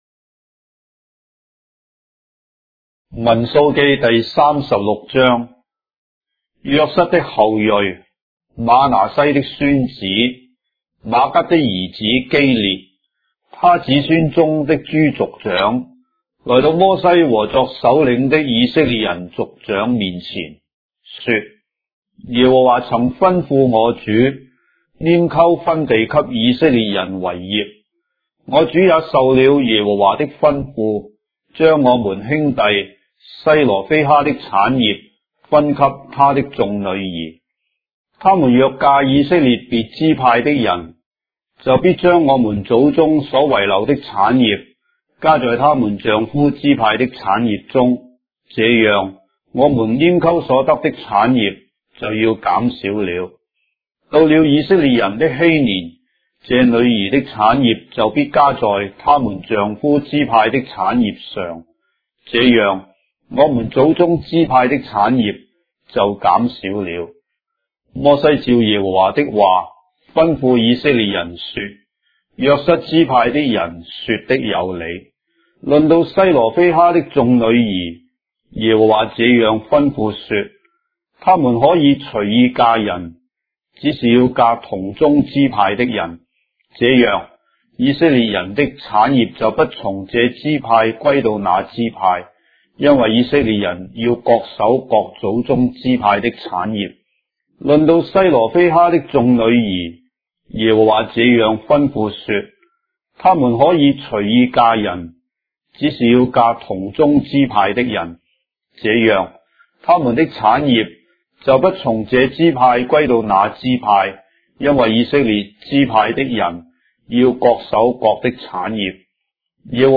章的聖經在中國的語言，音頻旁白- Numbers, chapter 36 of the Holy Bible in Traditional Chinese